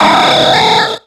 Cri de Deoxys dans Pokémon X et Y.